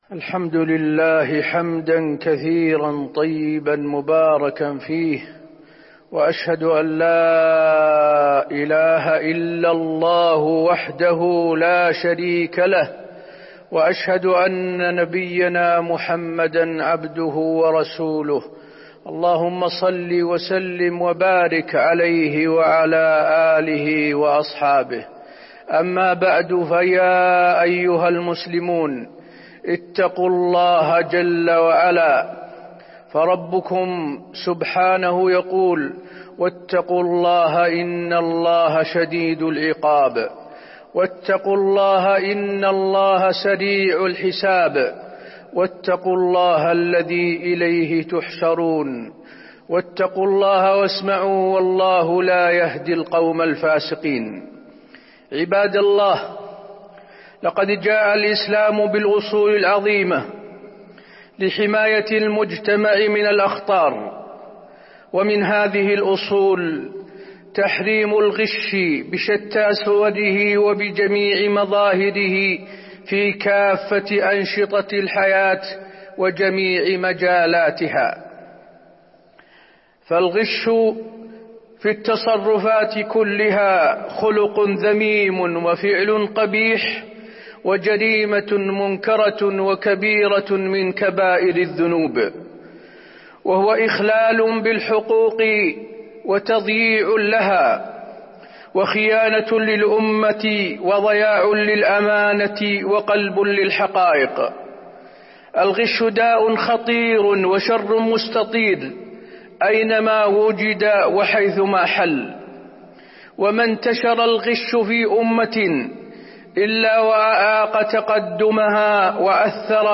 تاريخ النشر ٢ جمادى الآخرة ١٤٤٢ هـ المكان: المسجد النبوي الشيخ: فضيلة الشيخ د. حسين بن عبدالعزيز آل الشيخ فضيلة الشيخ د. حسين بن عبدالعزيز آل الشيخ تحريم الغش